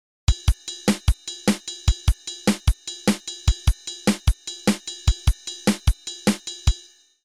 Start with an eighth-note ride pattern. Split it palindromatically across two sound sources, using two limbs, on two sides of the kit.
The result is surprisingly nonlinear; most beats are played in unison.